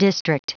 Prononciation du mot district en anglais (fichier audio)
Prononciation du mot : district